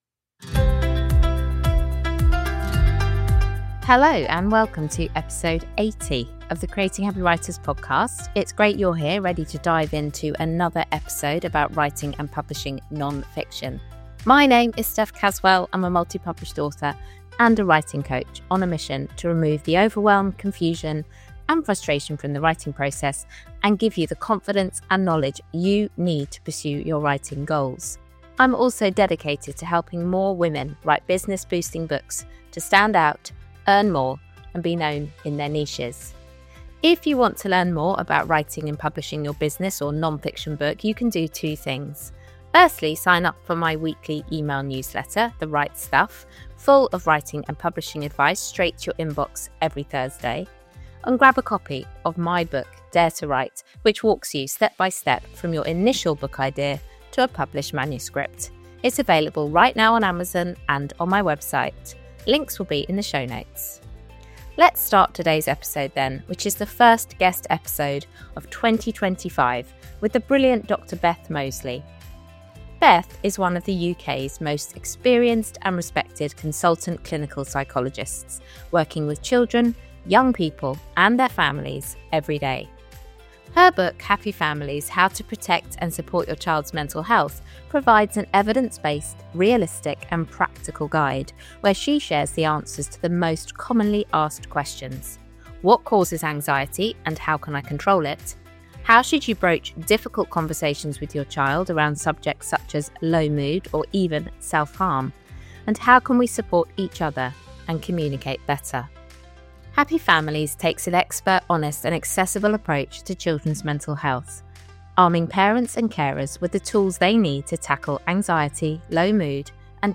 During our fantastic conversation, we talk about